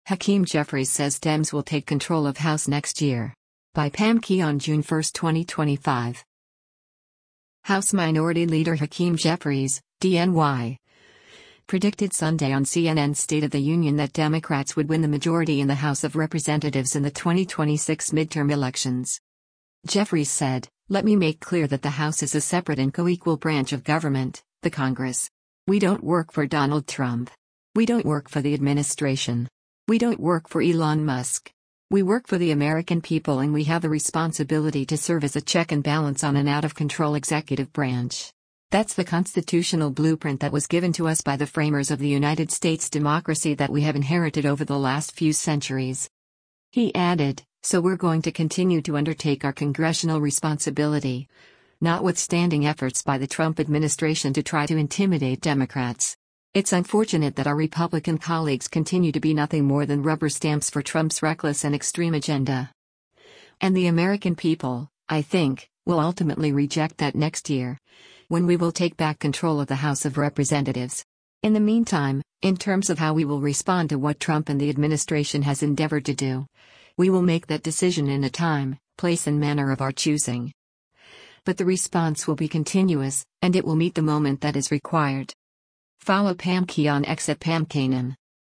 House Minority Leader Hakeem Jeffries (D-NY) predicted Sunday on CNN’s “State of the Union” that Democrats would win the majority in the House of Representatives in the 2026 midterm elections.